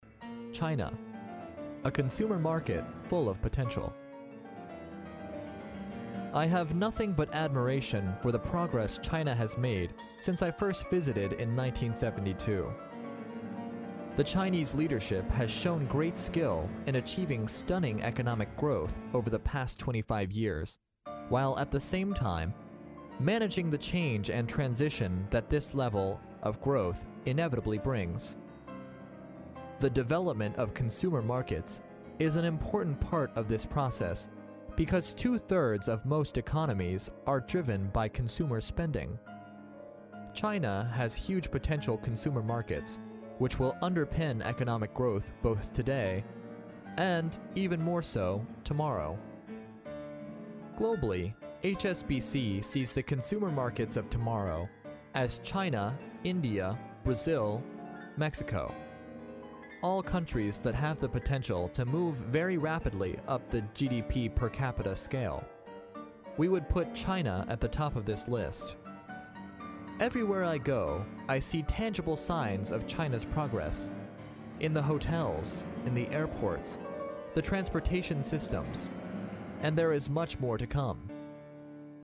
历史英雄名人演讲第36期:一个潜力巨大的消费者市场(1) 听力文件下载—在线英语听力室